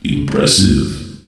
telefragged.ogg